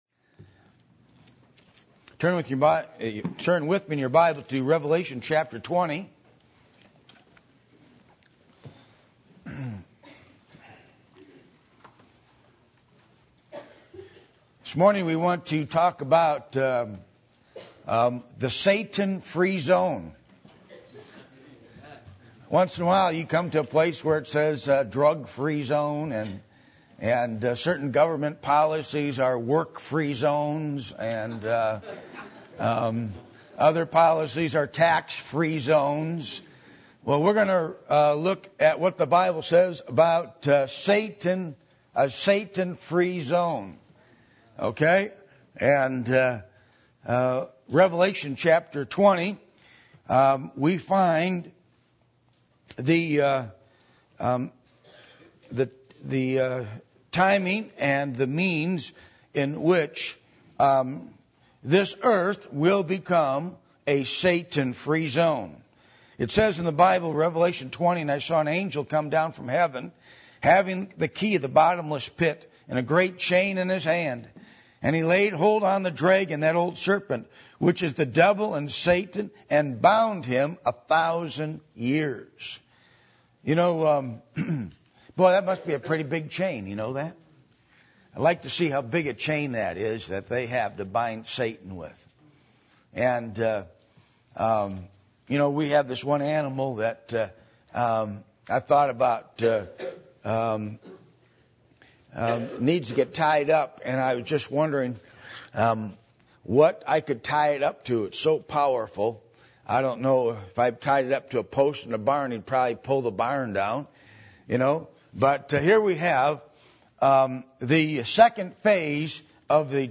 Passage: Revelation 20:1-15 Service Type: Sunday Morning %todo_render% « Baptism Of The Holy Spirit